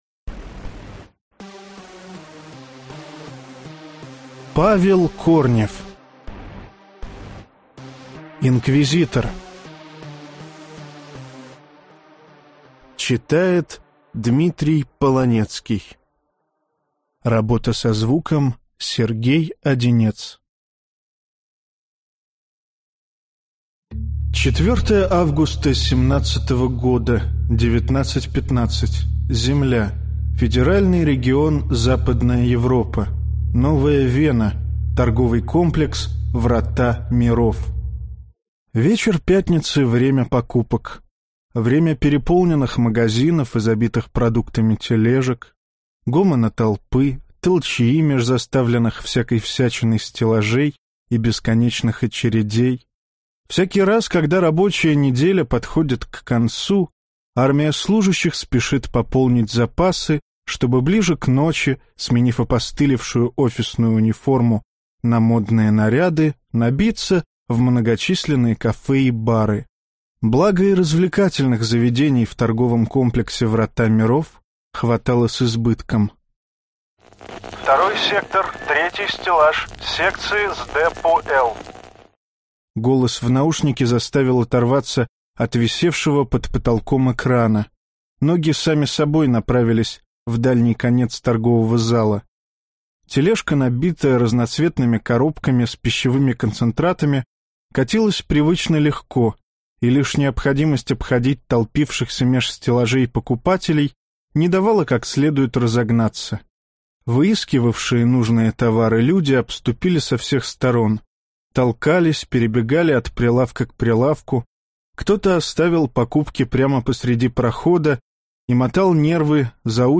Аудиокнига Инквизитор | Библиотека аудиокниг